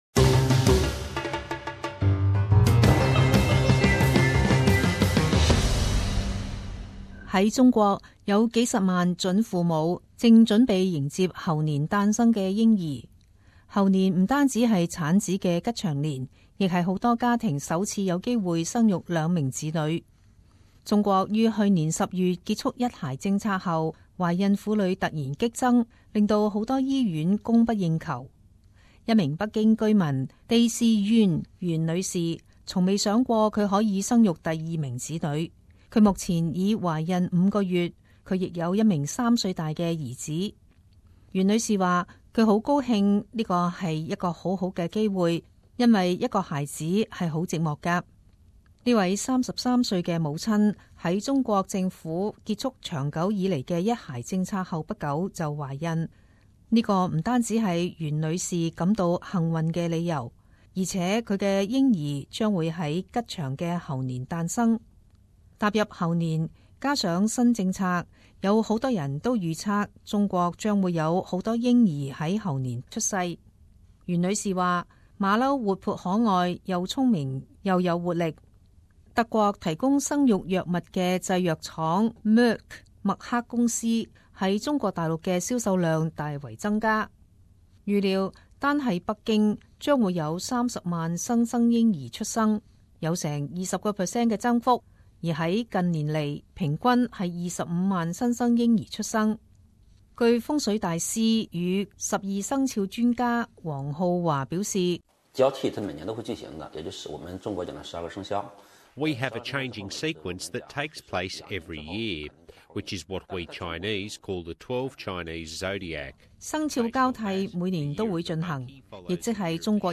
時事報導 - 中國孕婦趕在猴年產子